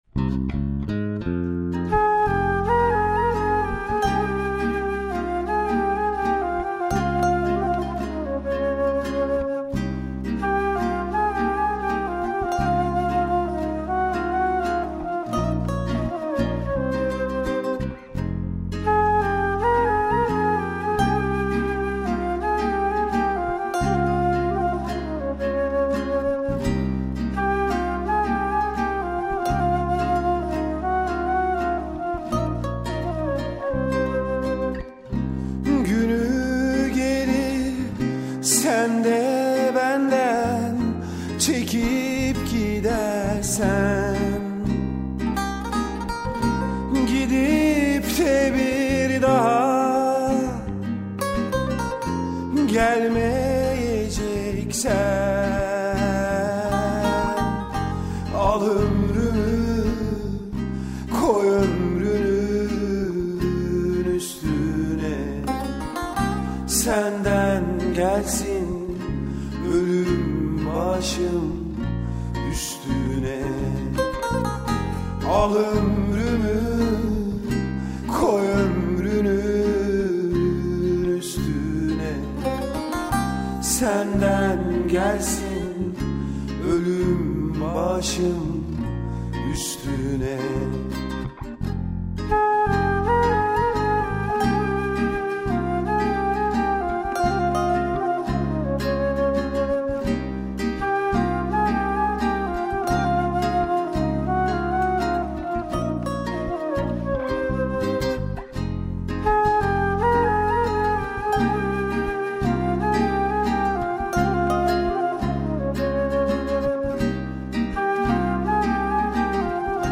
Anadolu' dan Türküler